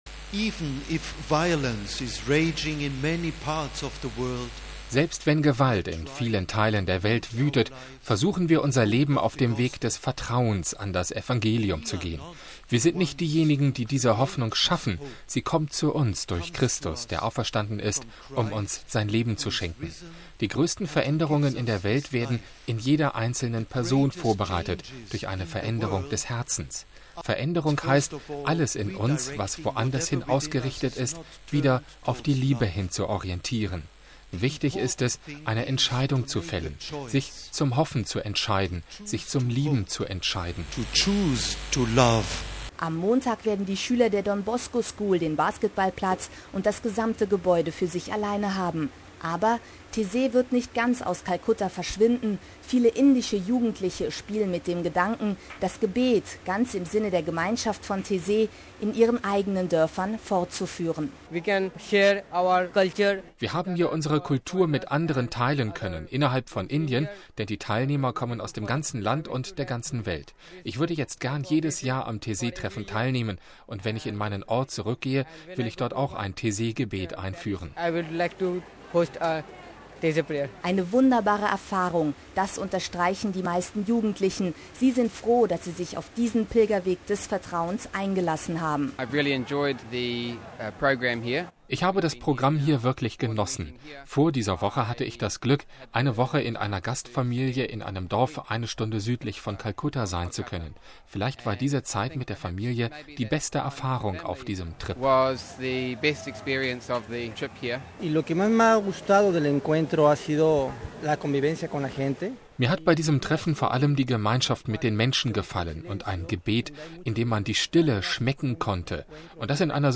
Ein Australier: „Ich habe das Programm hier wirklich genossen.
Ein Mexikaner: „Mir hat bei diesem Treffen vor allem die Gemeinschaft mit den Menschen gefallen und ein Gebet, in dem man die Stille schmecken konnte, und das in einer so armen Situation, mit so viel Trauer... und hier ein wenig Hoffnung schenken zu können.“